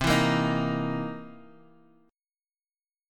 C7#9 chord